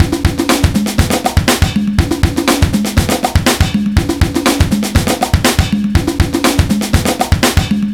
Marchin On 121bpm